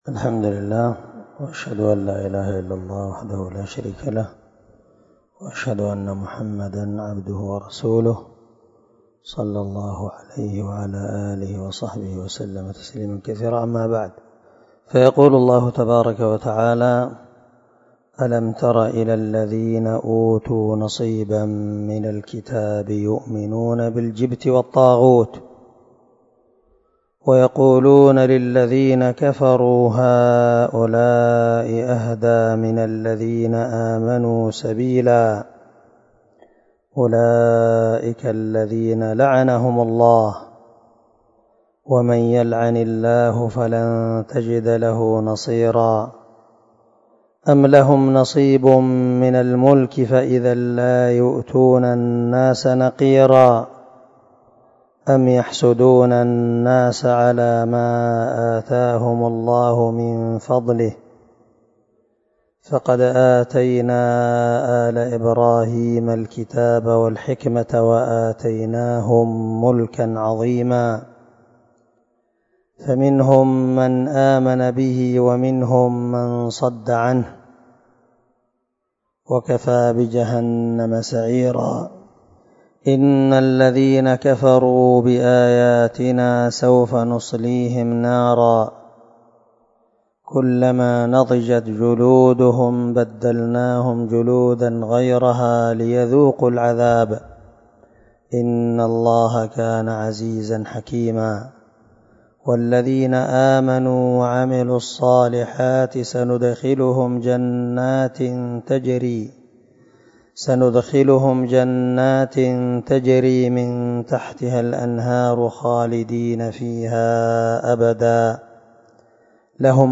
271الدرس 39 تفسير آية ( 51 - 57 ) من سورة النساء من تفسير القران الكريم مع قراءة لتفسير السعدي